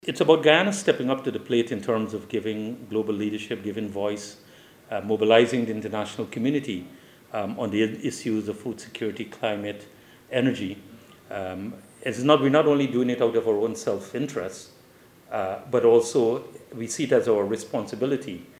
In an interview with NCN, Secretary Persaud underscored Guyana’s proactive stance in providing global leadership, advocating for these pressing issues, and mobilizing the international community.